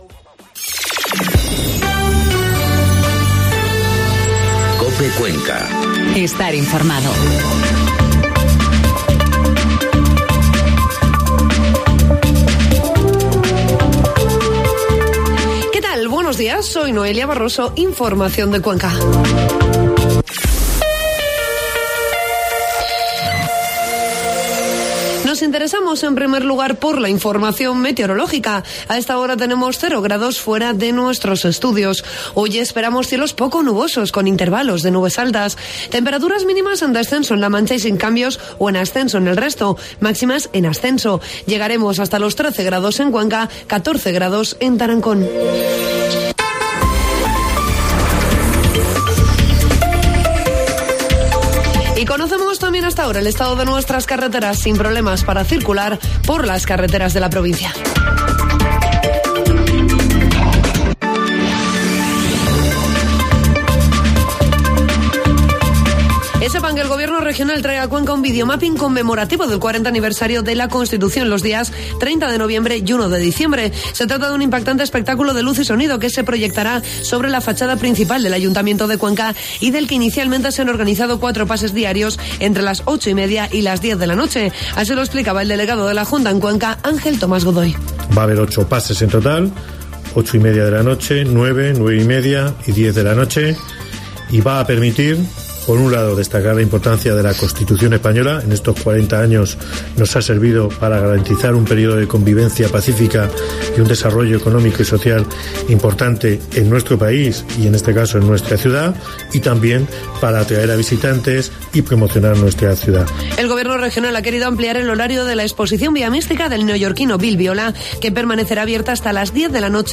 Informativo matinal COPE Cuenca 28 de noviembre